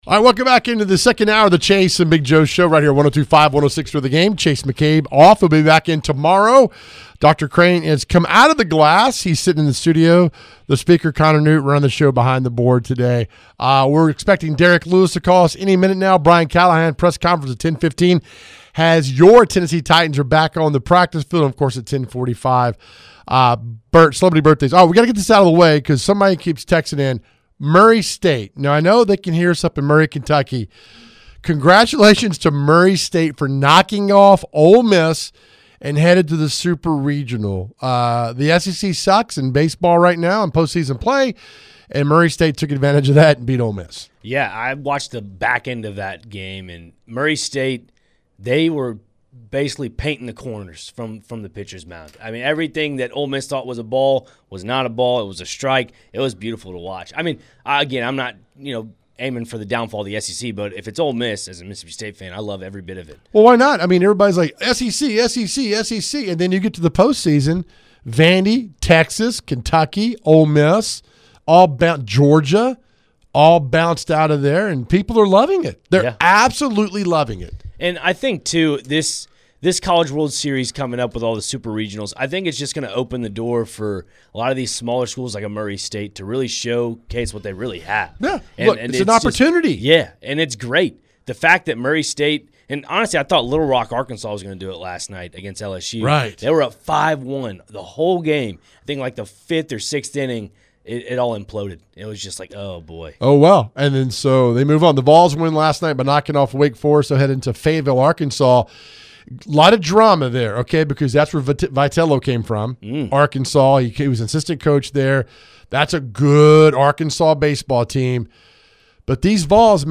In the second hour, Vols baseball recap and a little MLB talk to start it off. Brian Callahan's press conference was during the hour.